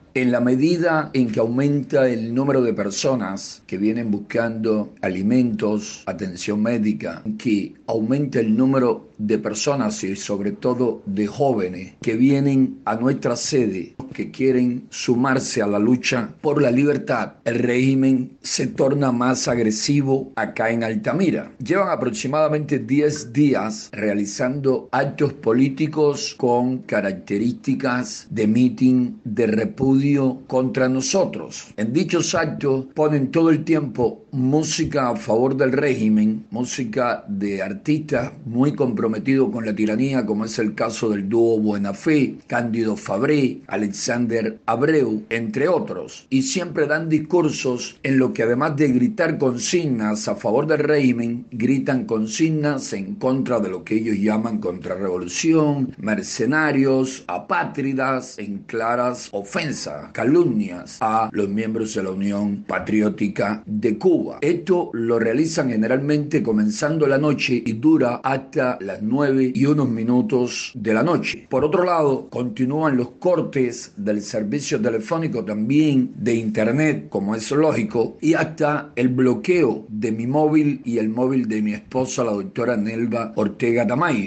José Daniel Ferrer denunció la situación a Radio Martí